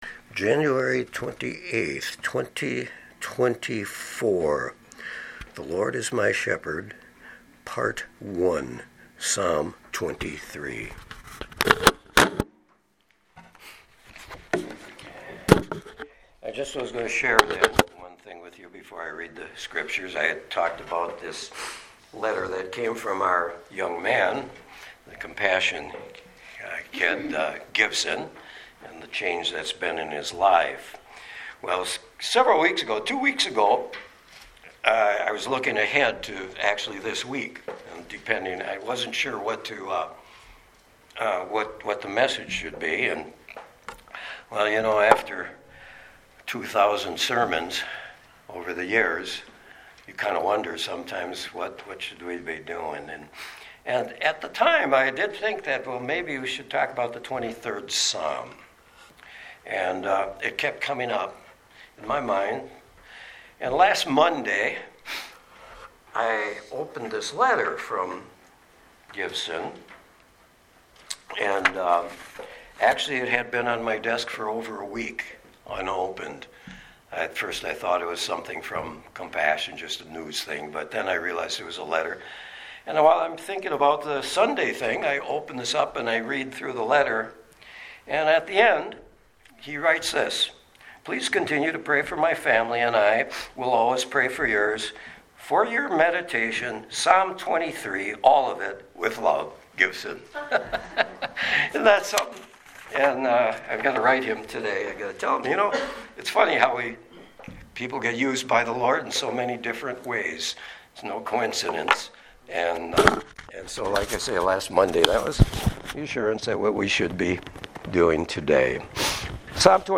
Sermons | Sand Lake Chapel
Guest Speaker